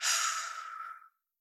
deepExhale1.wav